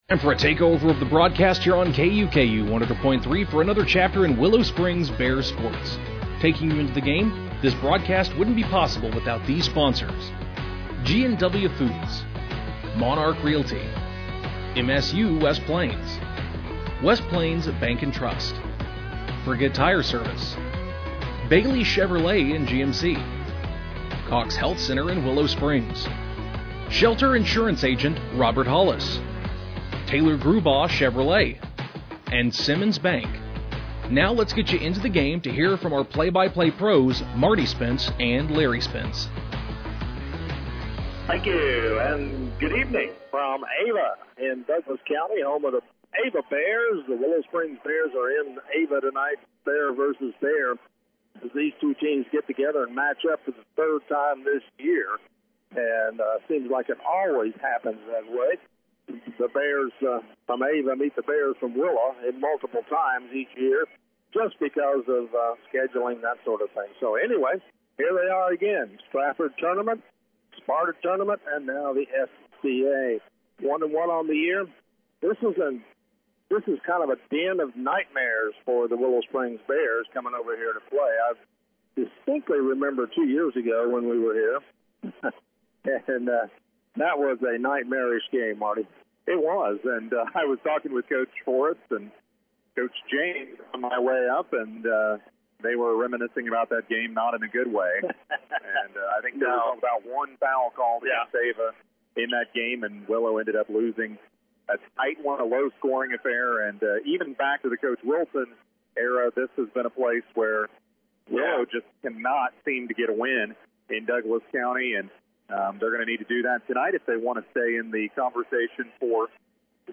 Game Audio Below: Willow won the tip and got a quick bucket to start the game.